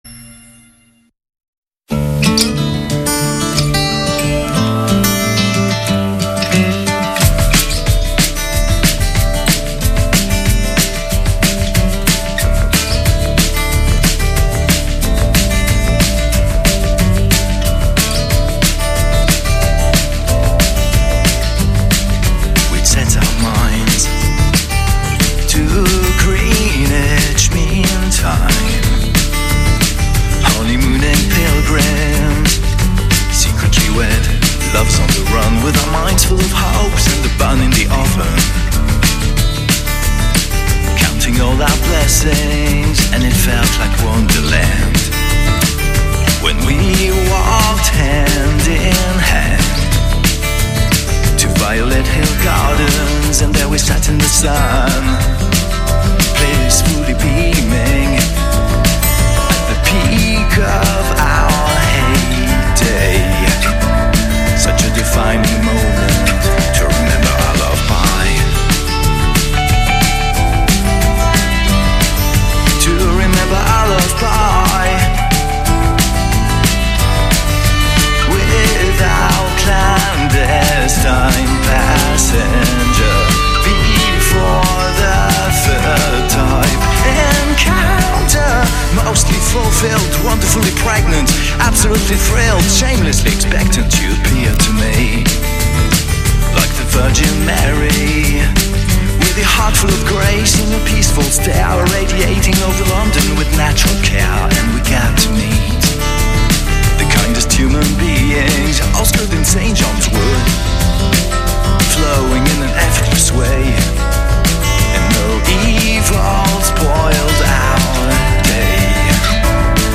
Rudy's Back est une émission de Ska, de Punk depuis 1995.